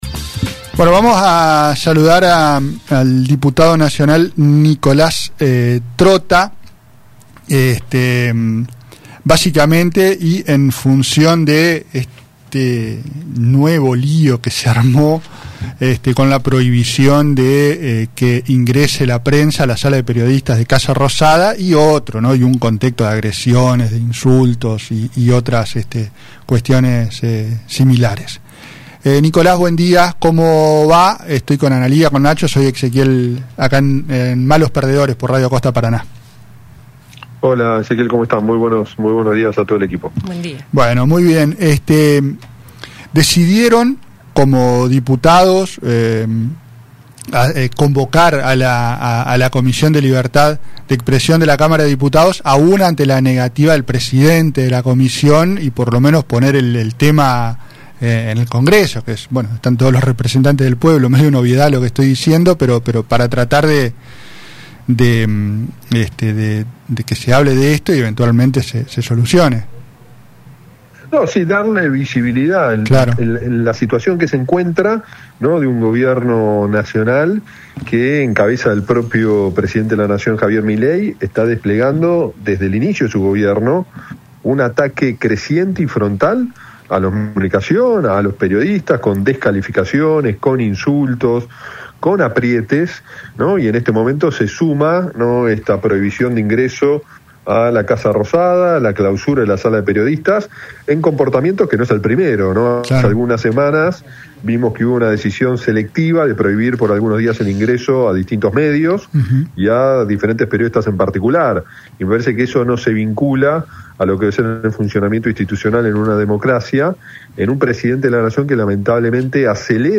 durante una entrevista con el programa Malos Perdedores